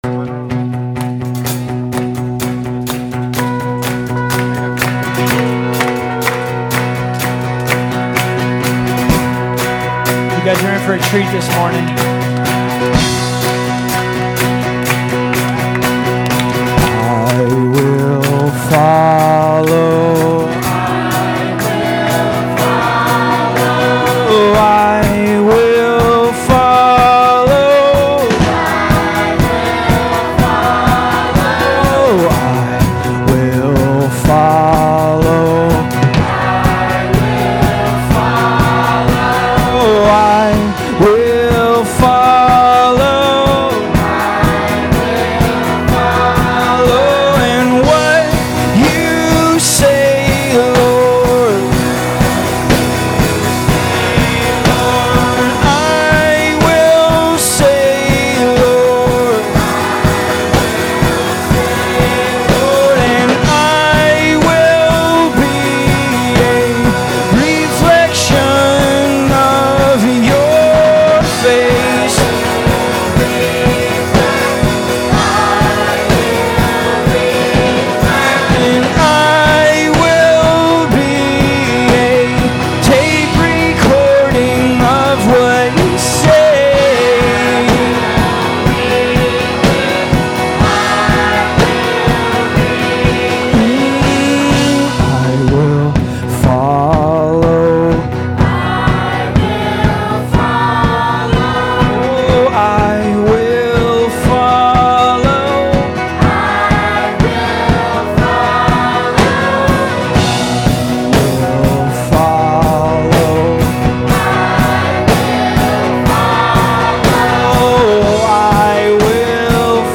Here is another raw recording for your listening pleasure.  This one will be especially enjoyable, it is from our Family Weekend with the choir!
Love the melody. even the love the soprano section that’s going on. love it love it love it.